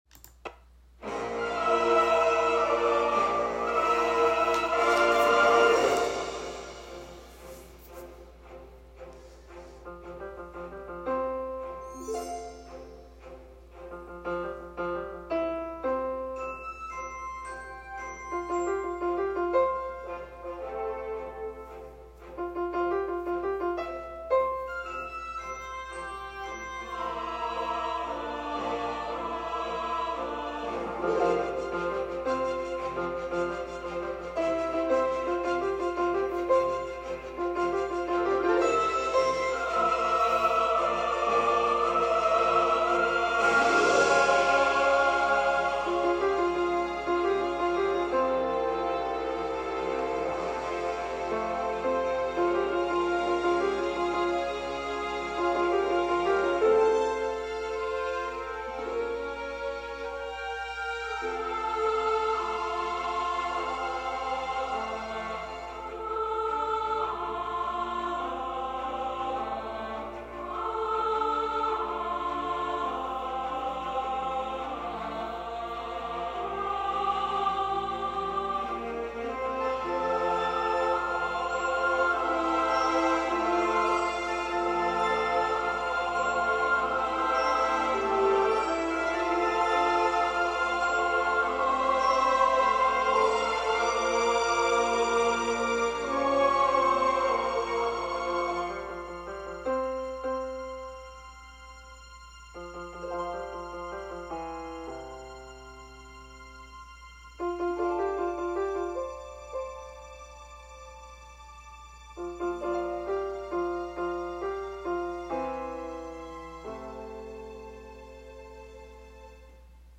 This is a piano reduction.